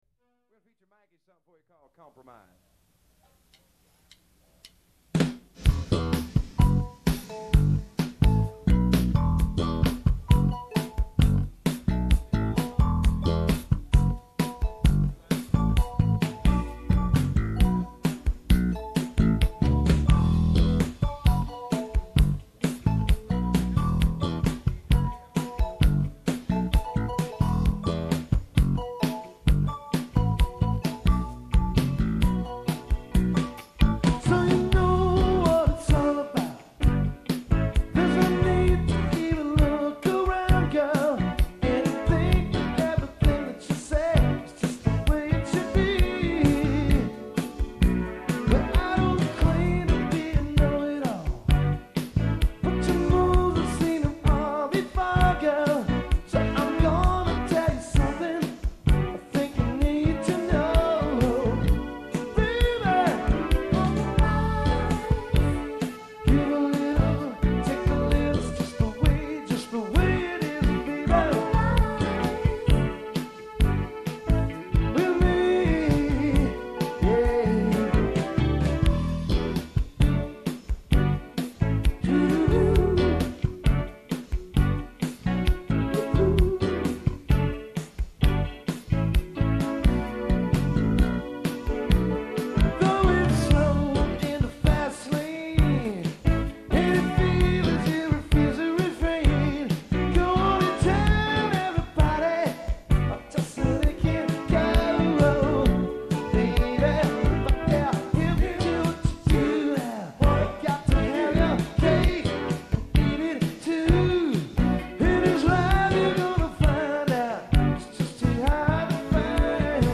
Drums
Keys & Trumpet
Vocals, Bass Guitar & Synth Bass
sax & flute